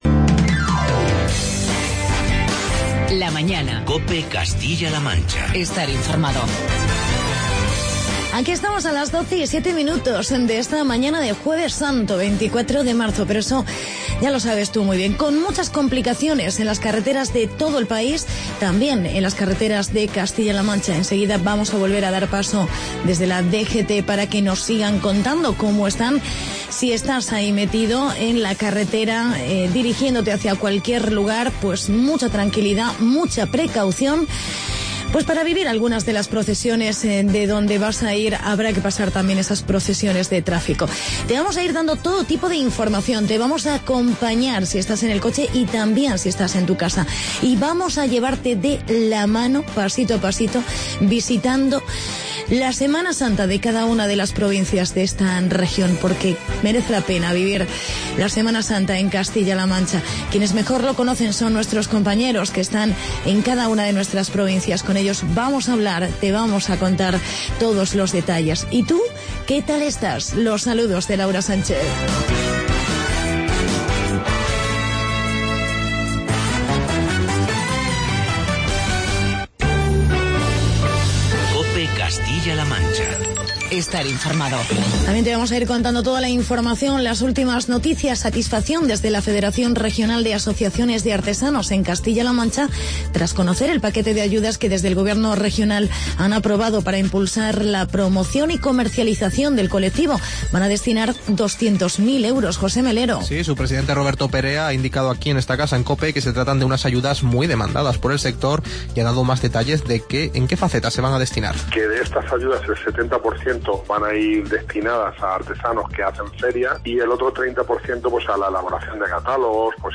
Programa especial Semana Santa en CLM. Reportajes de Albacete, Ciudad Real y Cuenca.